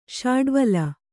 ♪ śaḍvala